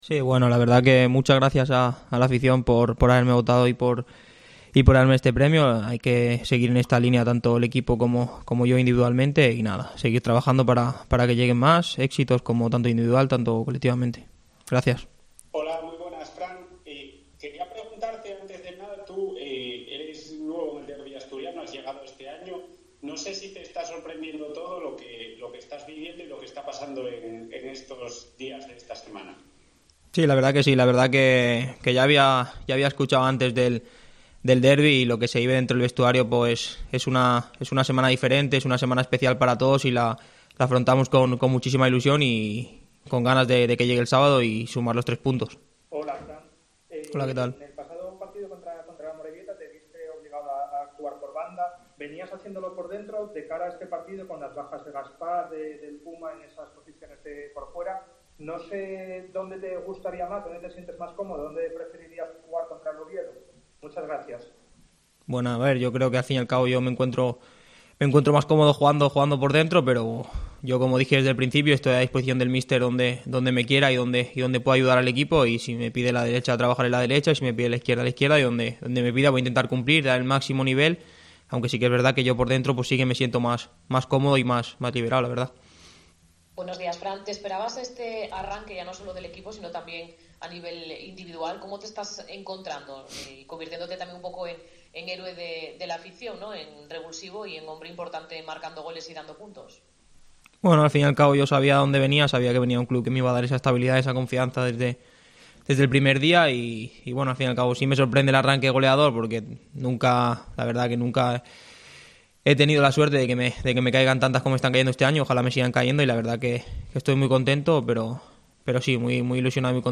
AUDIO: Rueda de prensa